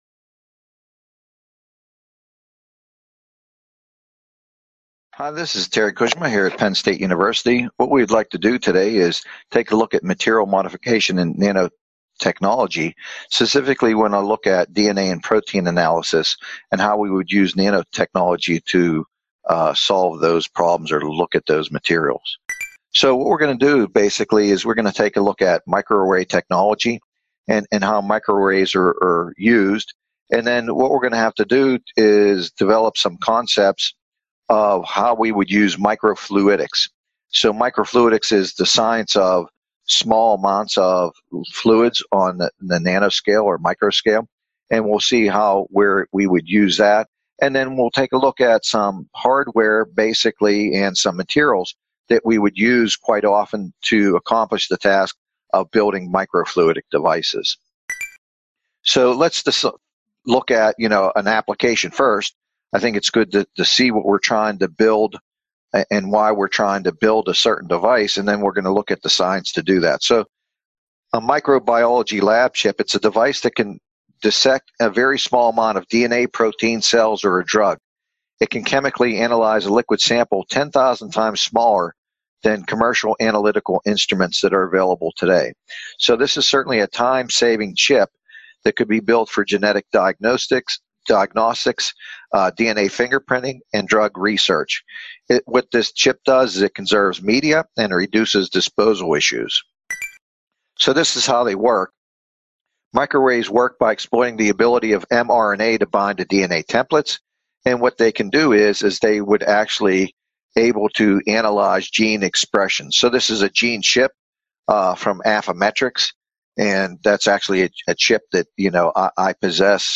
This video, provided by the Nanotechnology Applications and Career Knowledge Support (NACK) Center at Pennsylvania State University, is part one of a two-part lecture on materials modification in nanotechnology, specifically focusing the use of nanotechnology for DNA and protein analysis.